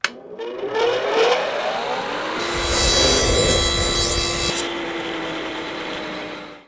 hand mining
cuttingtool.wav